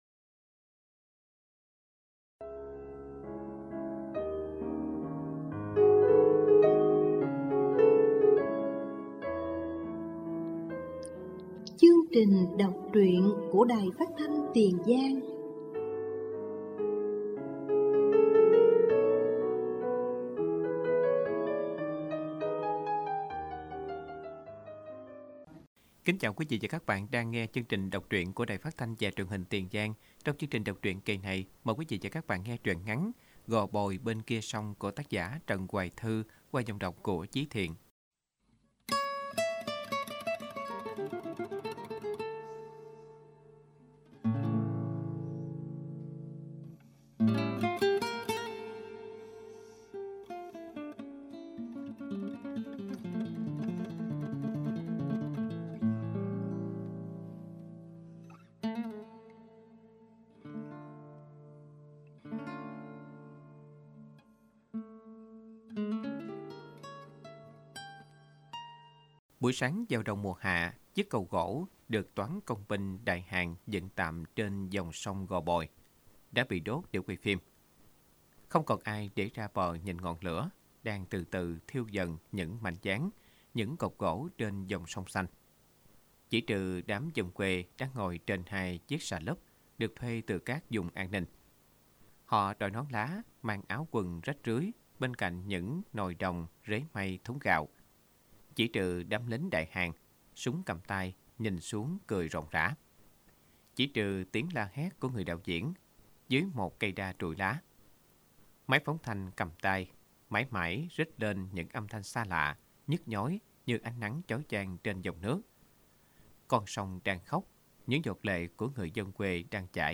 Đọc truyện “Gò bồi bên kia sông” của Trần Hoài Thương